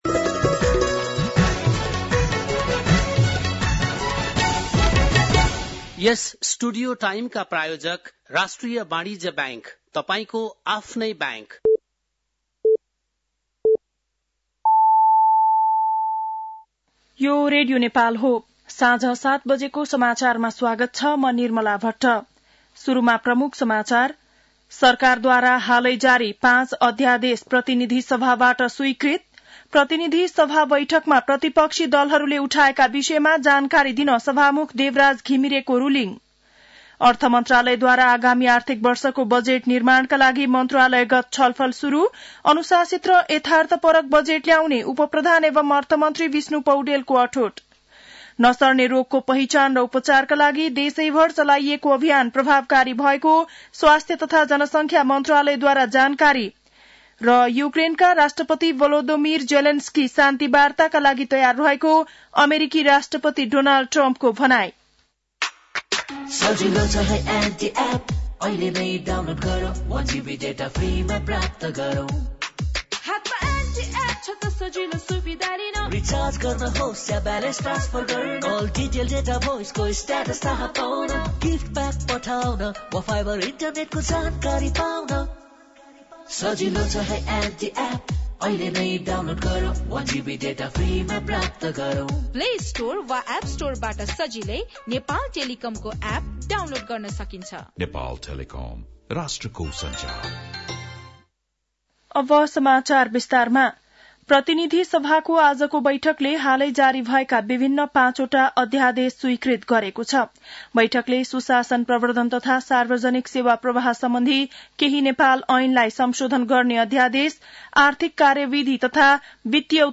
बेलुकी ७ बजेको नेपाली समाचार : २२ फागुन , २०८१